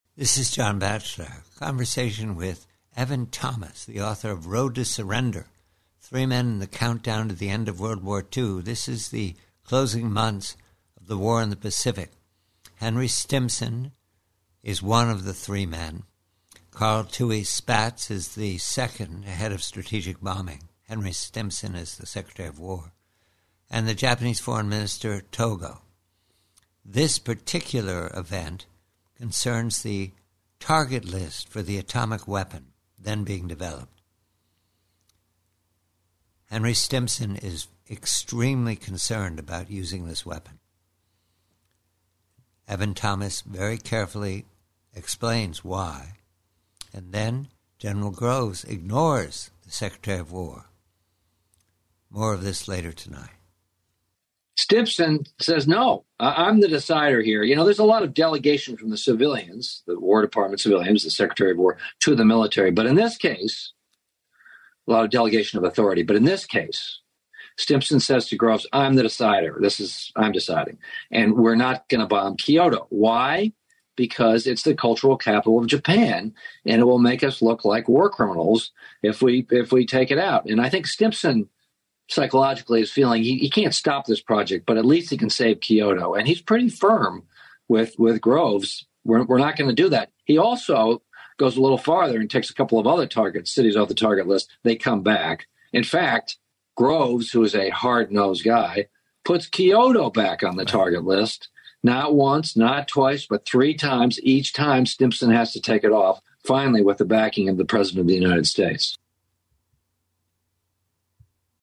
Conversation with authord Evan Thomas